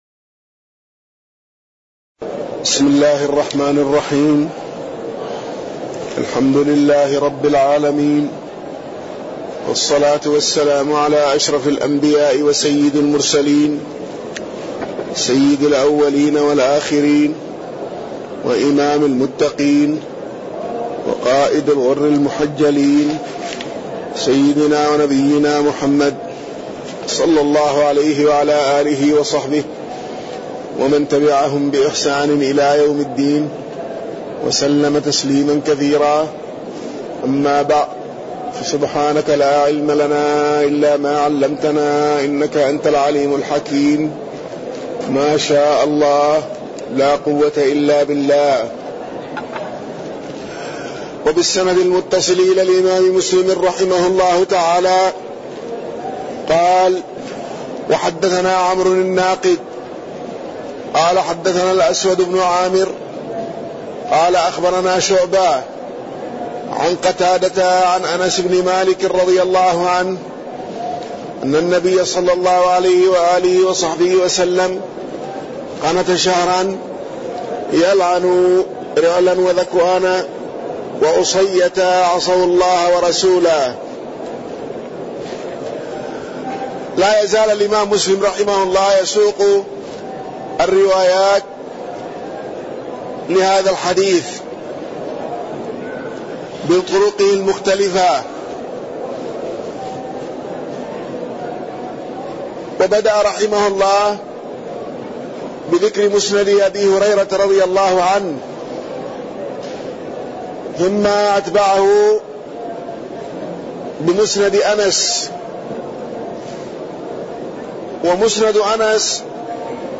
تاريخ النشر ٢٦ ربيع الثاني ١٤٣٠ هـ المكان: المسجد النبوي الشيخ